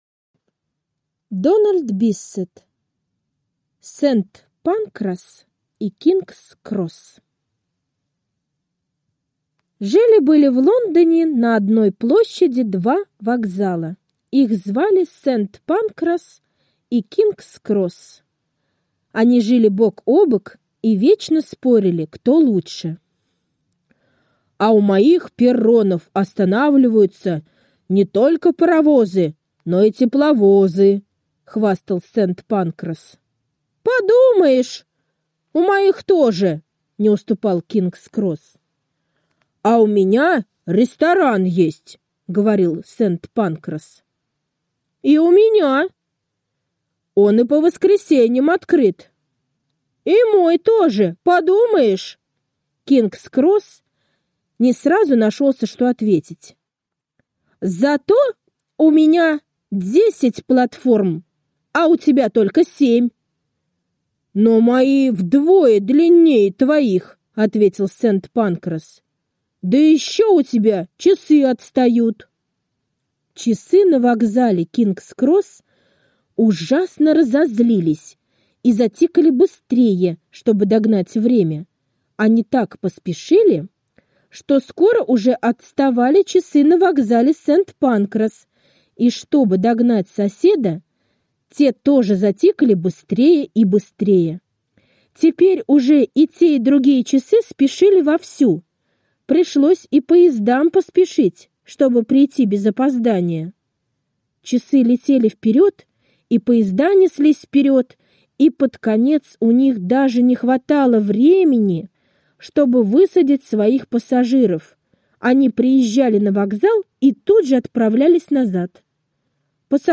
Сент-Панкрас и Кингс-Кросс - аудиосказка Дональда Биссета. О споре двух вокзалов и о мудром решении этого спора мэром города и королевой.